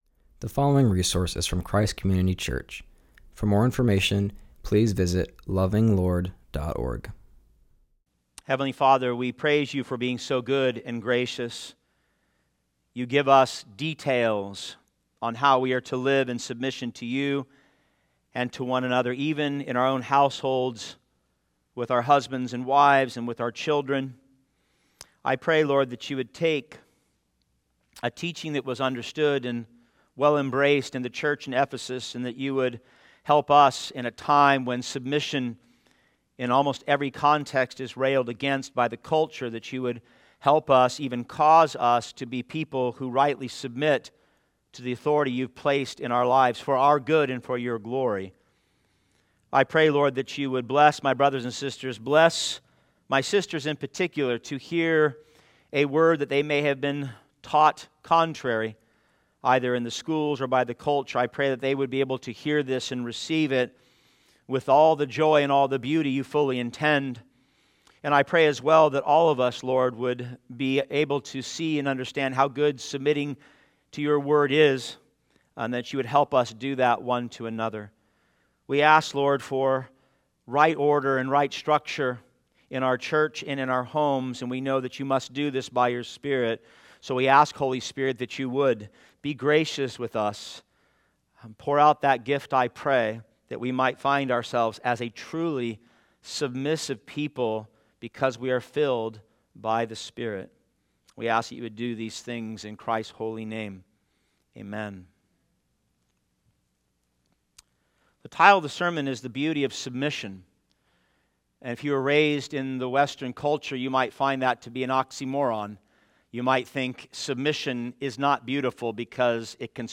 continues our series and preaches from Ephesians 5:22-24.